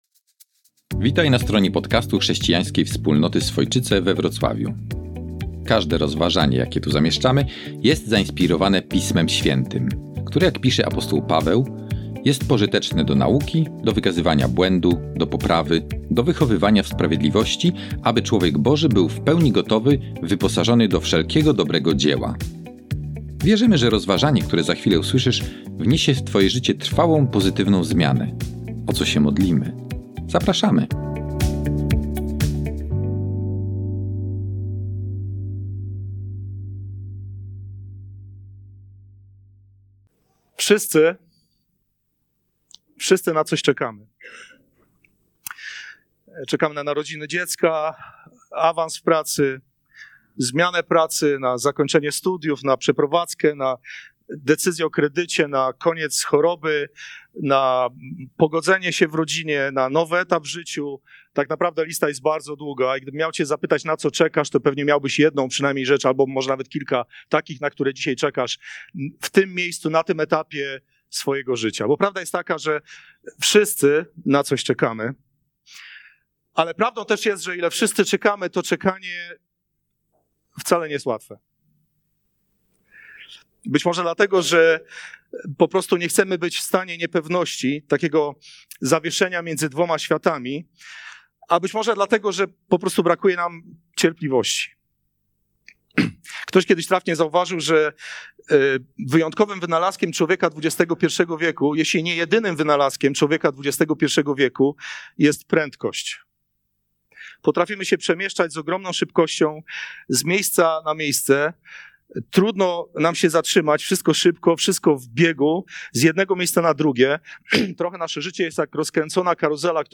Nauczanie z dnia 22 grudnia 2024